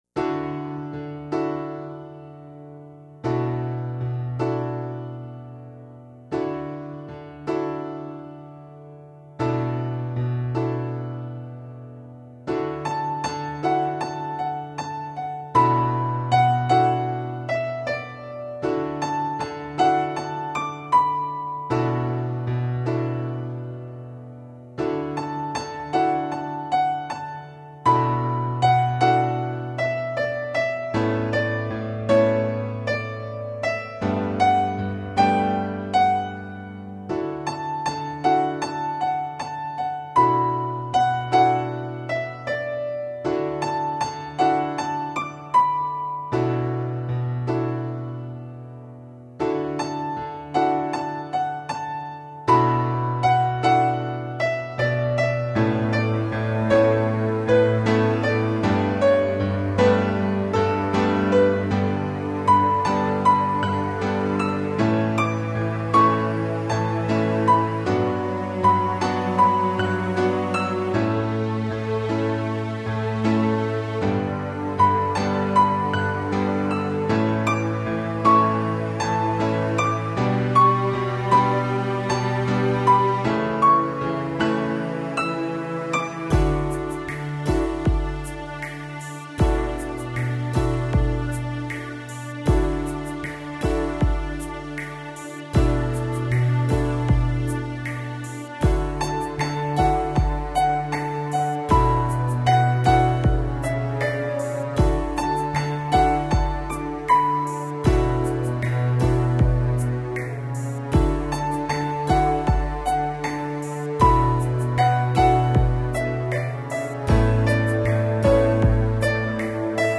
Below is a sampling of original instrumental songs in which he plays guitar, bass, drum programming, and keyboards.